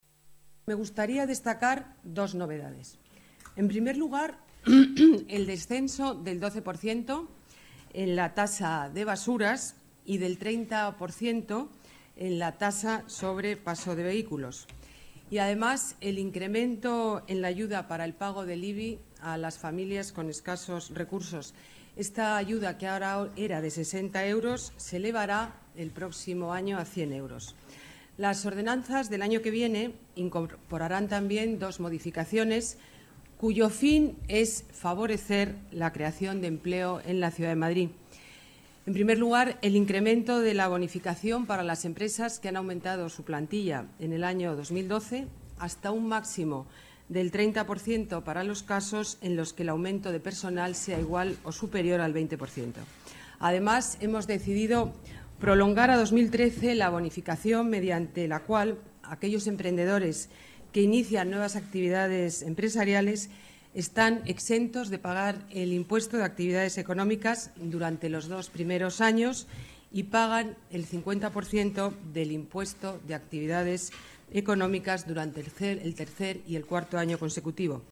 Nueva ventana:Declaración de la alcaldesa, Ana Botella, ordenanzas fiscales